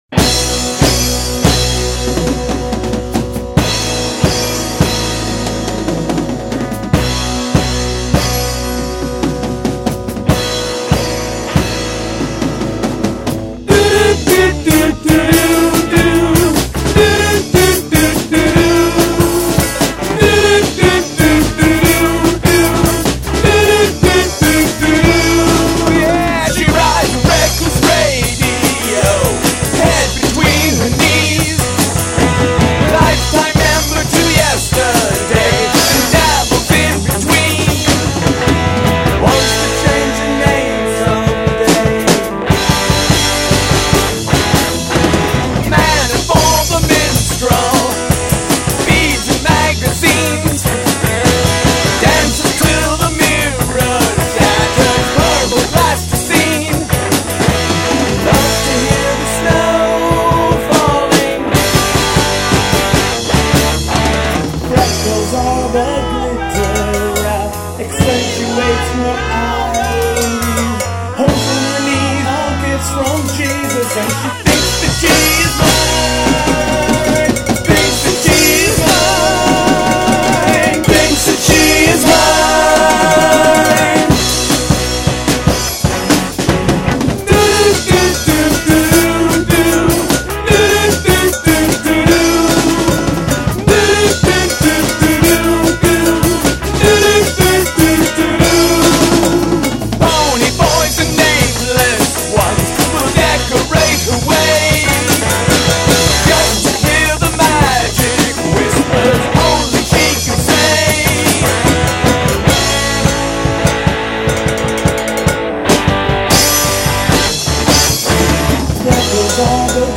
an "adjusted" version of one of our favorite songs